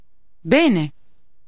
Ääntäminen
IPA : /faɪn/ US : IPA : [faɪn]